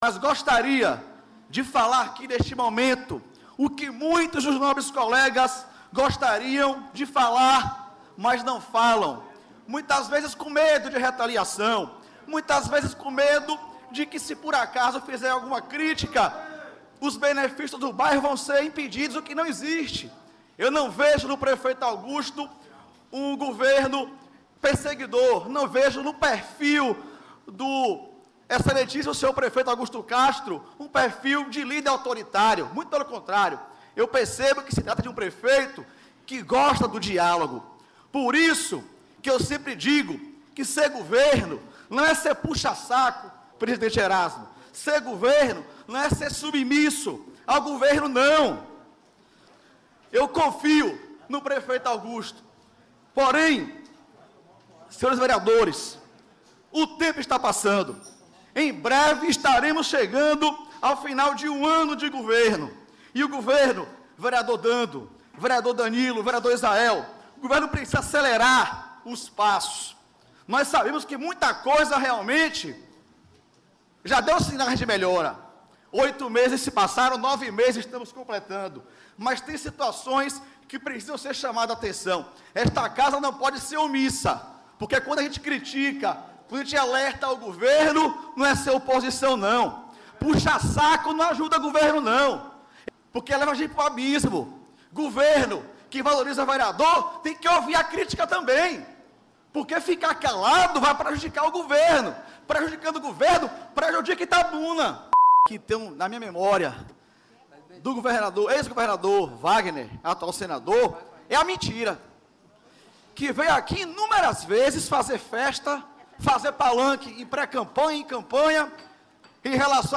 Clima quente entre parlamentares antecipa palanque de campanha ao Governo do Estado, durante sessão da Câmara Municipal de Itabuna, nesta quarta-feira (15). O momento mais tenso da sessão foi quando o líder do governo, o vereador Manoel Porfírio (PT) respondeu ao vereador Solon Pinheiro (SDD).
Em dado momento, o presidente da Casa, Erasmo Ávila (PSD), pede respeito aos colegas.
clima-quente-camara.mp3